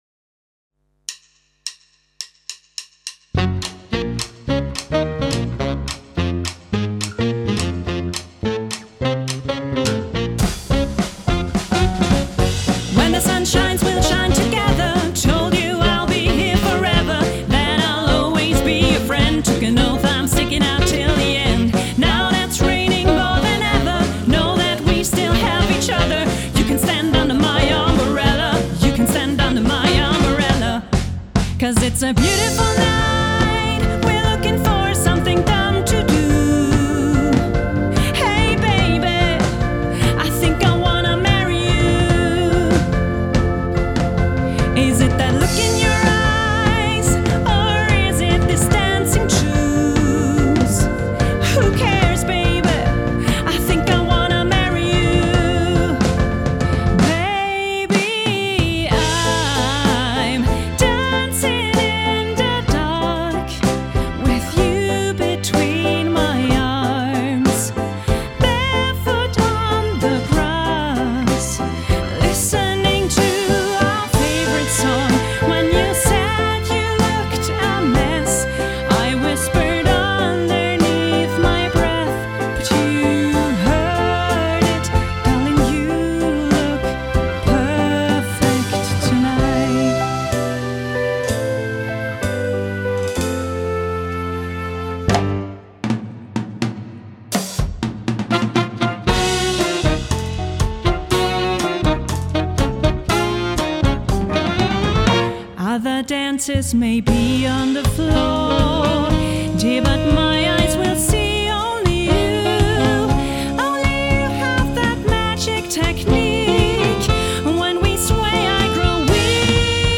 Quintett